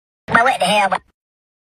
Boy_what_the_hell_boy_Sound_Effect.mp3